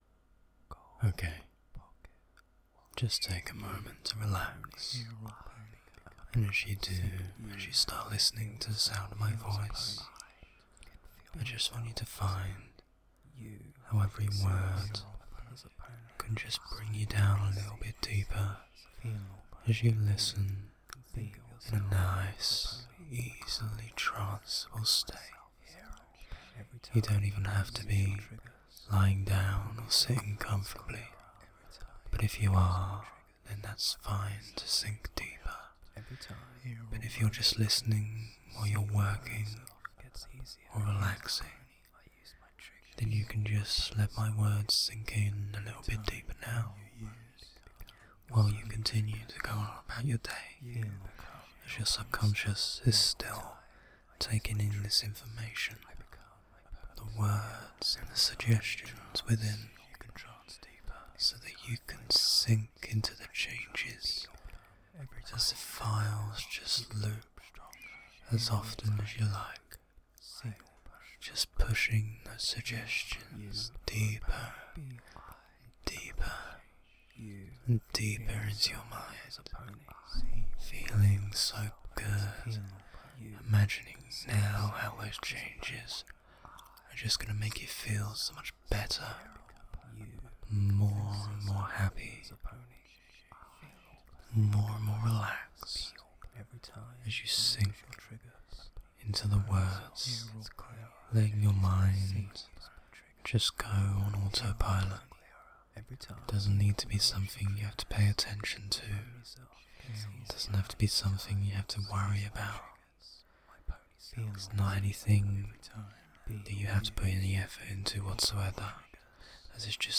I Watch It For The Plot Pony/Audio/Fan-made/Hypnosis & ASMR/Pony Hypno/Pony files
Pony Trigger Reinforcement.mp3